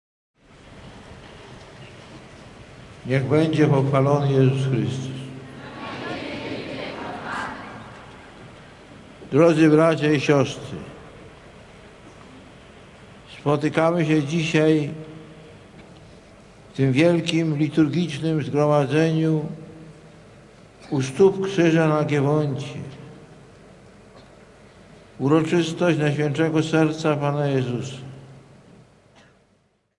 Ojciec Święty w Ojczyźnie - 1997 Wielka Krokiew w Zakopanem
Ksiądz Arcybiskup Metropolita Krakowski Kardynał Franciszek Macharski
Burmistrz miasta Zakopane - pan Adam Curuś